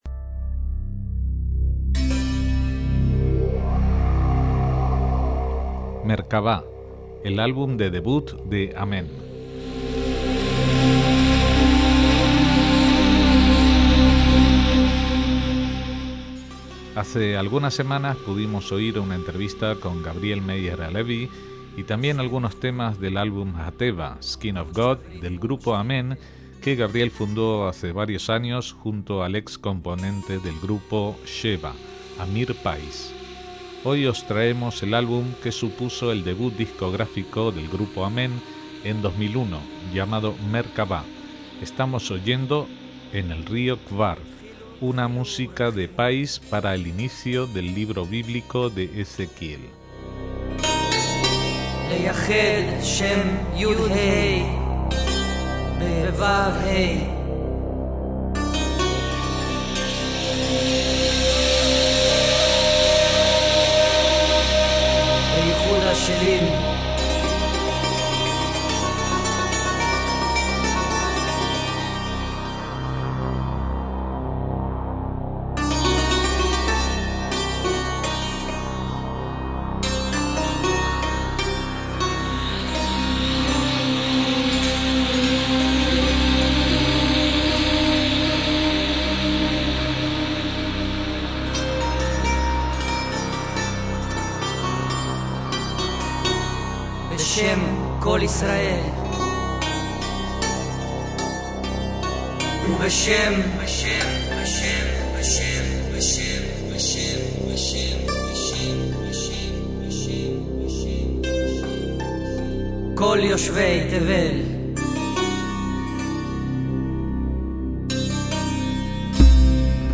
mayoritariamente instrumental
new age
una especie de trance espiritual a través del sonido